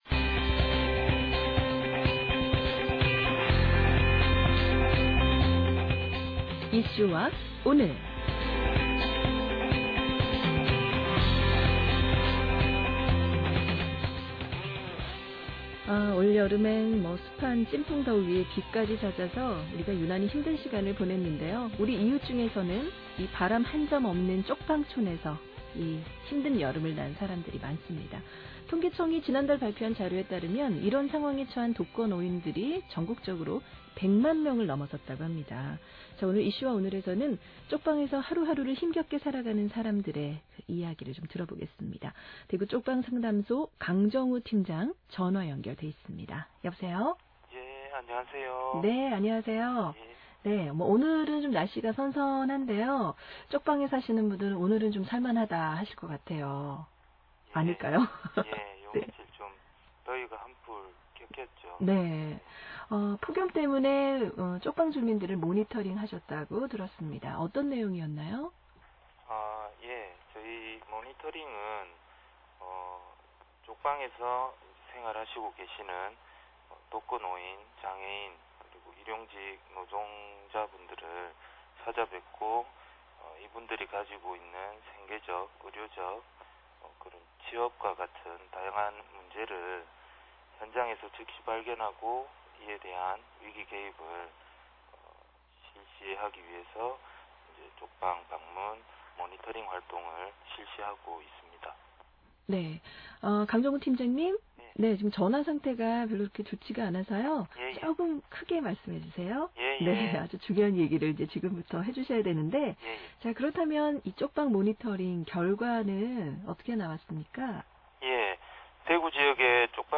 행복한 세상 931 라디오 인터뷰] "이슈와 오늘"